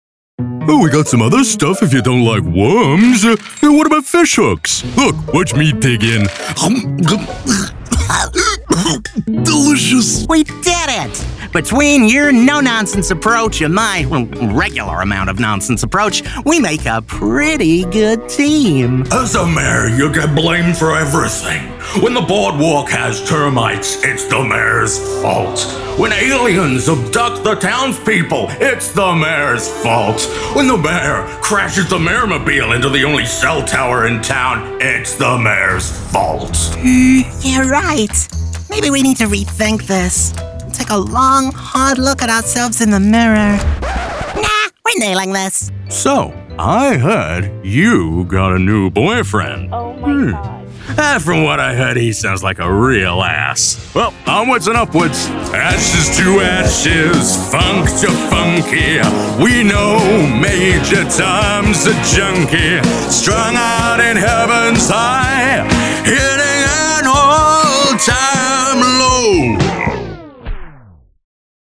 Animation & Dubbing
I can do zany and quirky characters or give performances filled with pathos and emotion.
Demo